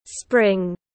Mùa xuân tiếng anh gọi là spring, phiên âm tiếng anh đọc là /sprɪŋ/
Spring /sprɪŋ/